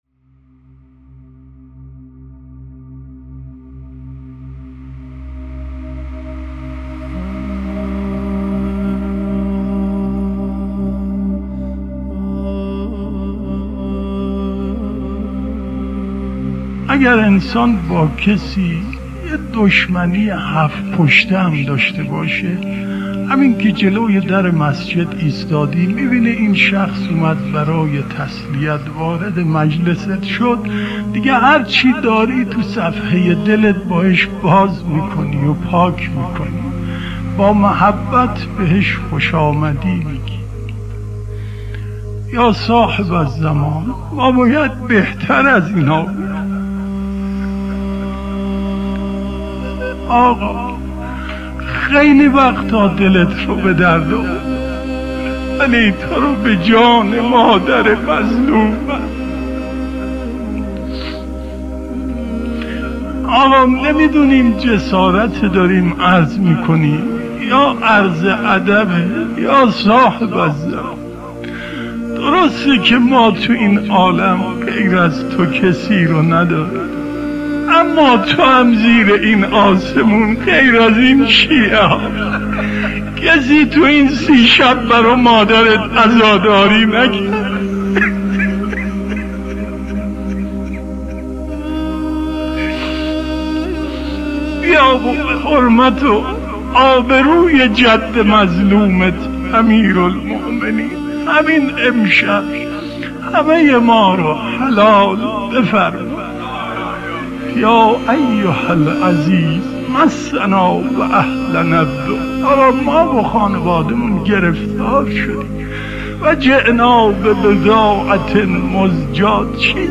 به مناسبت ایام فاطمیه مجموعه پادکست «نگین شکسته» با هدف بیان بندگی و فضائل حضرت فاطمه زهرا(س) با کلام اساتید بنام اخلاق به کوشش ایکنا گردآوری و تهیه شده است، که چهلمین قسمت این مجموعه با کلام آیت‌الله مجتبی تهرانی با عنوان «ما را حلال کن» تقدیم مخاطبان گرامی ایکنا می‌شود.